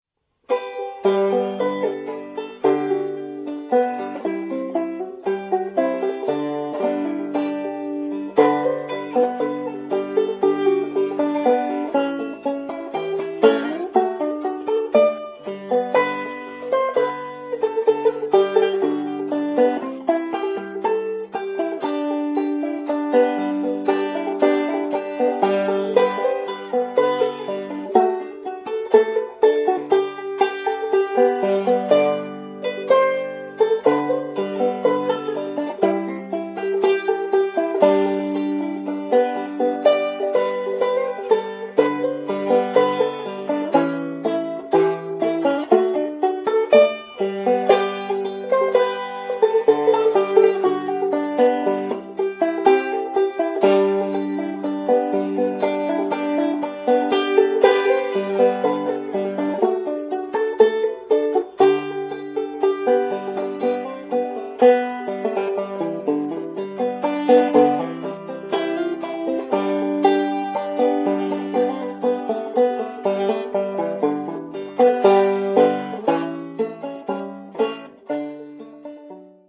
play Sound ClipIt's mostly traditional American music
.  play Sound ClipHe plays banjos and 12-string guitar